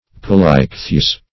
Search Result for " paleichthyes" : The Collaborative International Dictionary of English v.0.48: Paleichthyes \Pa`le*ich"thy*es\, n. pl.